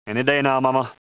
Voice actor Jeff Bennett is the voice of Johny Bravo, plus many others in the cartoon.